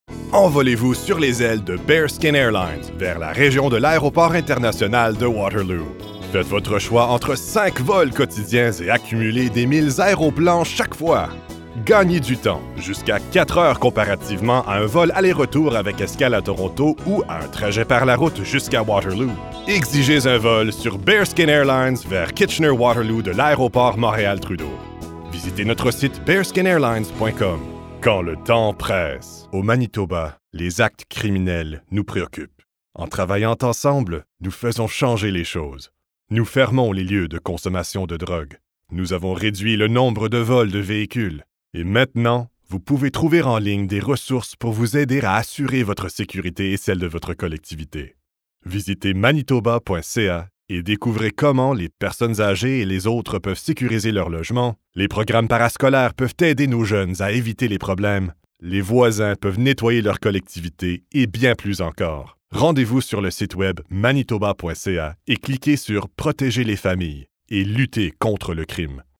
French Voice Demo